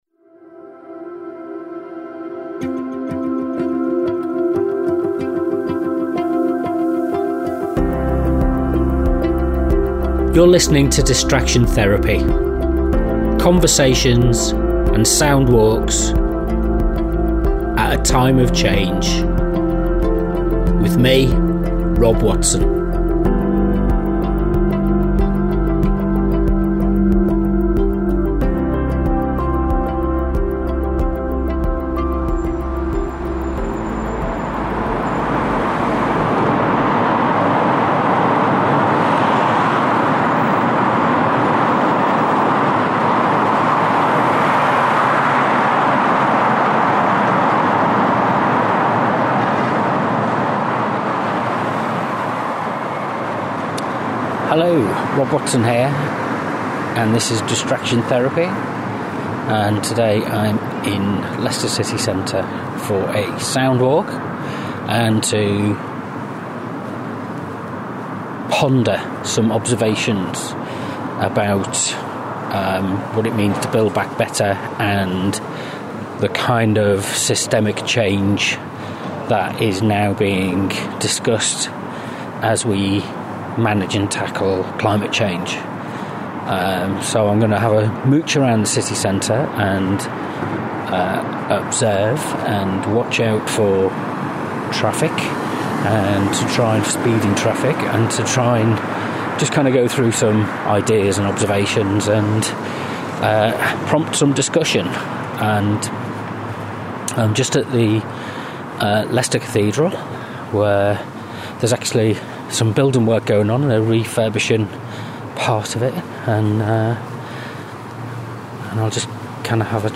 This morning I went for a walk into Leicester City Centre, and recorded some thoughts reflecting on the need for total systems change that has been identified in the IPCC report about climate change.